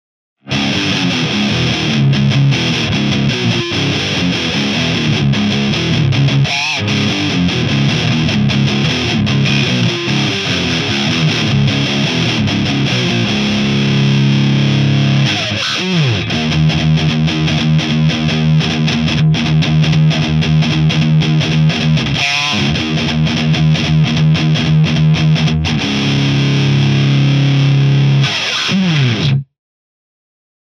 FERNANDESのプックアップVH-401とEMG 81のサンプルサウンドです。
ハイゲインと結構言われているのでやってみました。
アンプはJCM2000 DSL100です。